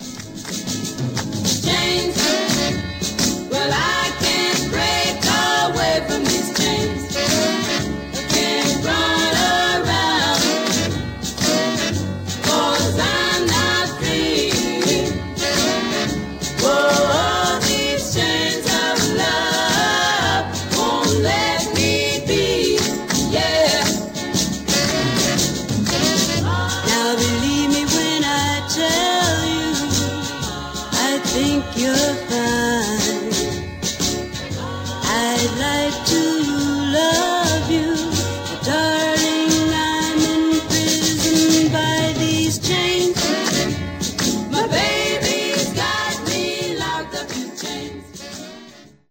R&B vocal trio